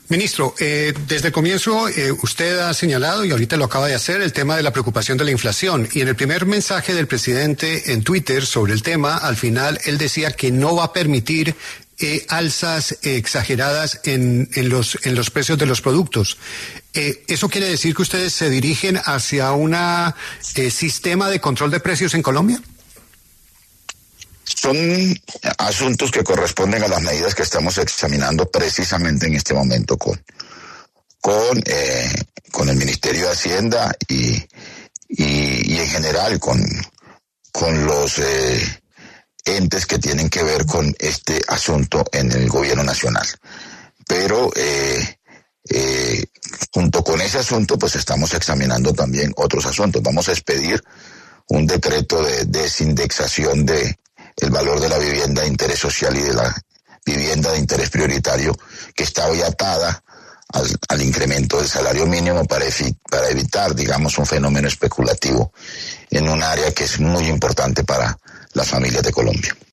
El ministro de Trabajo, Antonio Sanguino, reveló en primicia en La W, con Julio Sánchez Cristo, que el Gobierno Nacional expedirá un decreto de desindexación de viviendas VIS y VIP del salario mínimo.